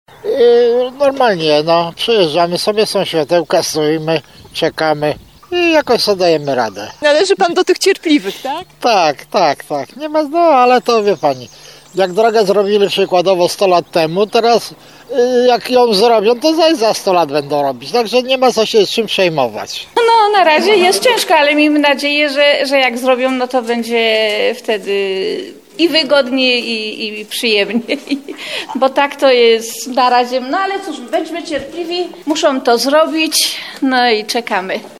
Podobnie uważa mieszkaniec Międzybrodzia: – są światełka, stoimy, czekamy i jakoś sobie dajemy radę.